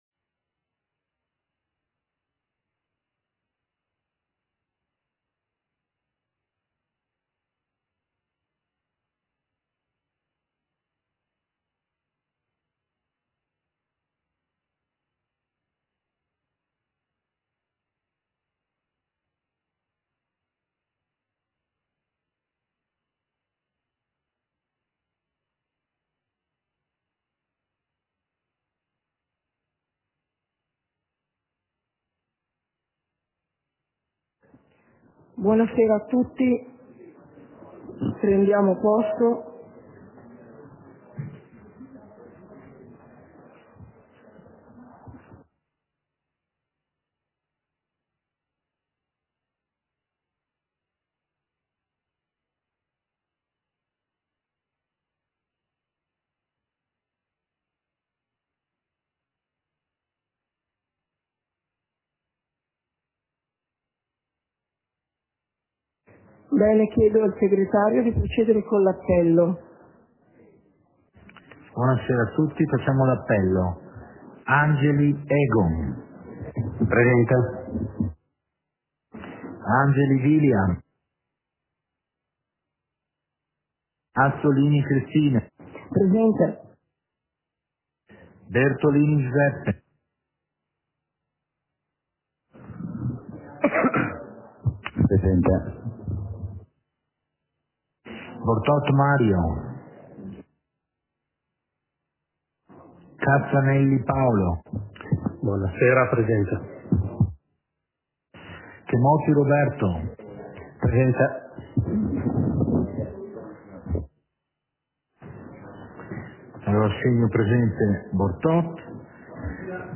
Seduta del Consiglio comunale - 10.01.2023